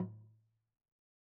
LogDrumLo_MedM_v1_rr2_Sum.wav